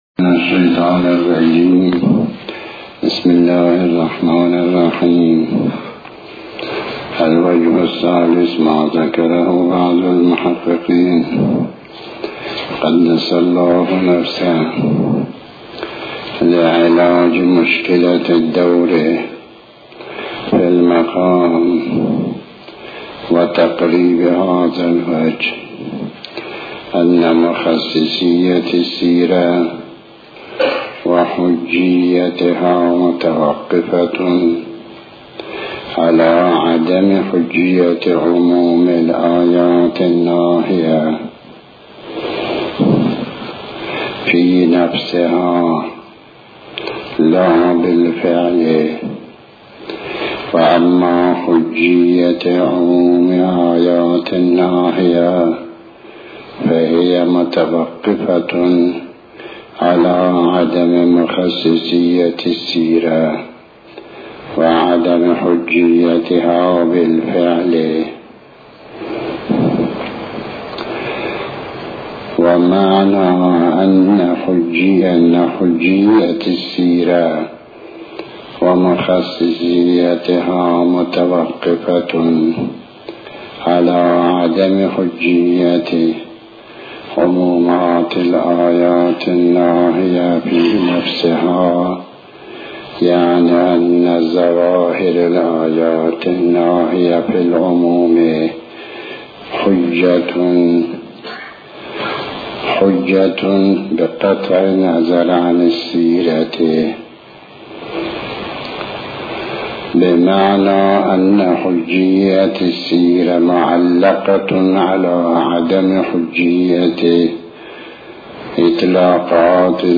تحمیل آیةالله الشيخ محمداسحاق الفیاض بحث الأصول 38/02/01 بسم الله الرحمن الرحيم الموضوع : أدلة حجية خبر الواحد – السيرة العقلائية الوجه الثالث : ما ذكره بعض المحققين(قده) [1] لعلاج مشكلة الدور في المقام.